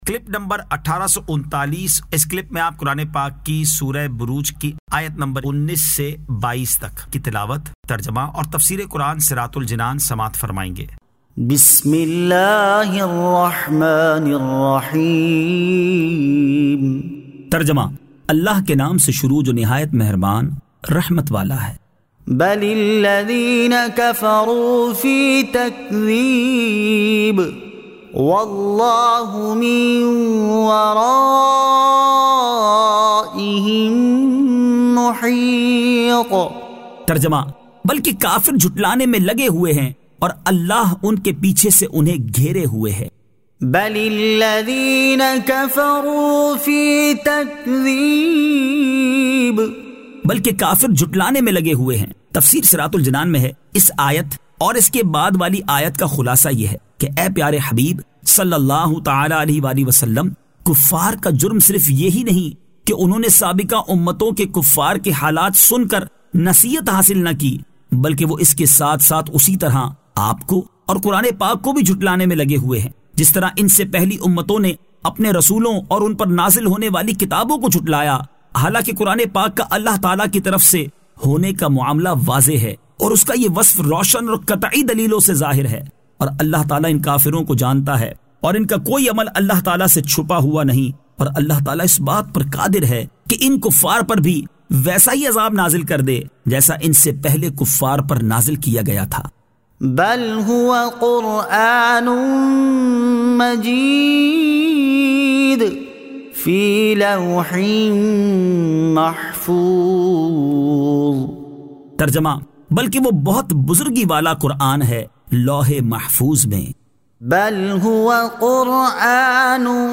Surah Al-Burooj 19 To 22 Tilawat , Tarjama , Tafseer